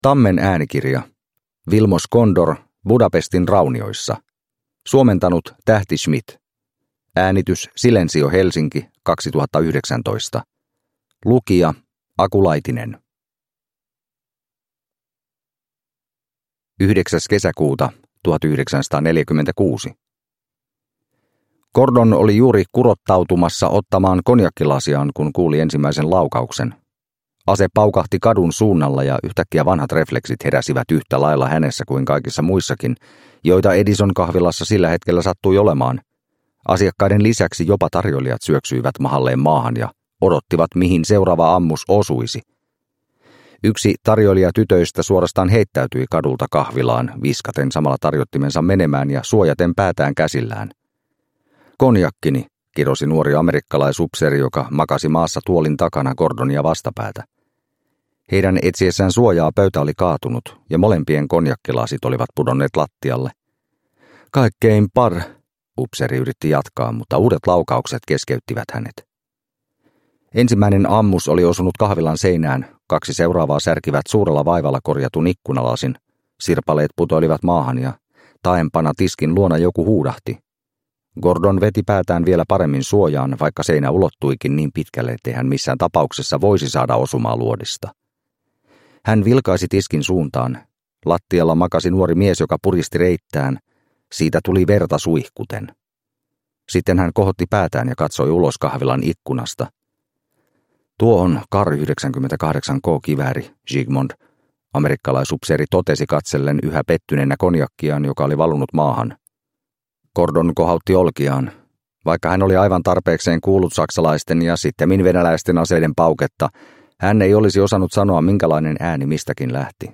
Budapestin raunioissa – Ljudbok – Laddas ner